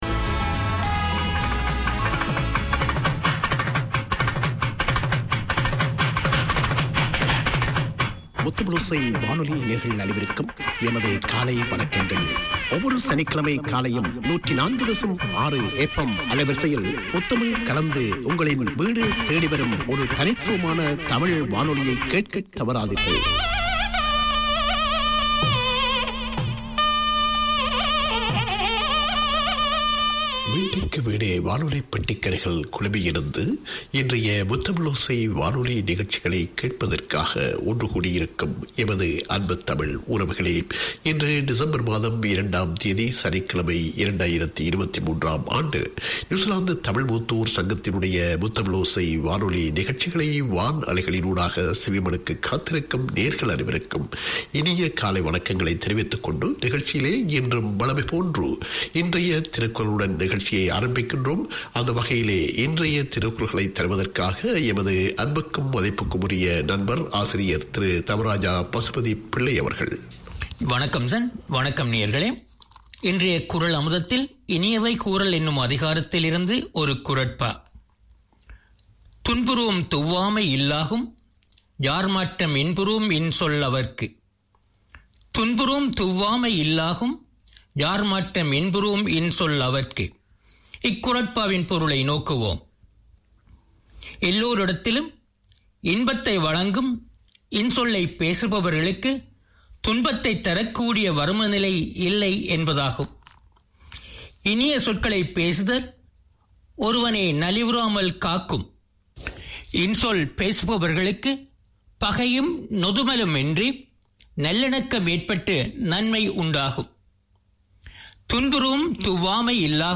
Tamil seniors are up early every Saturday to hear their half hour of radio. On air is a wealth of interviews, music, news, community news, health information, drama and literature.